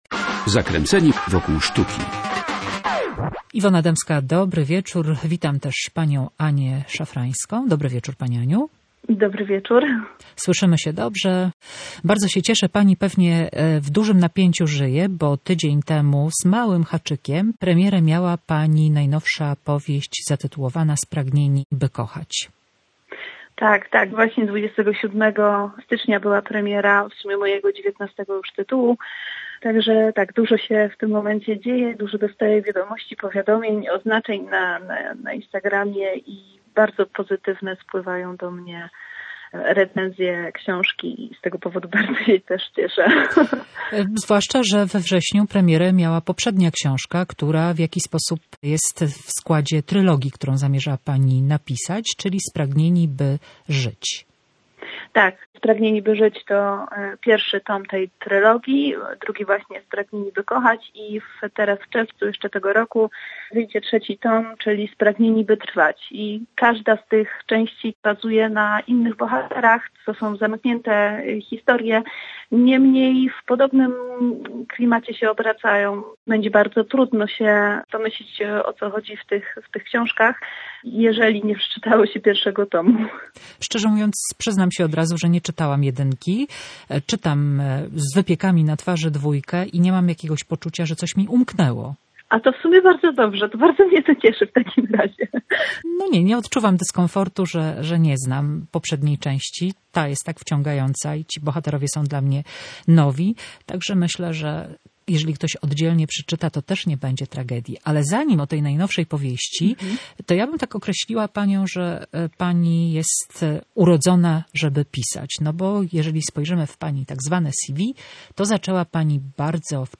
Zakręceni wokół sztuki Spragnieni, by kochać. Rozmowa